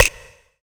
1TI85RIM  -R.wav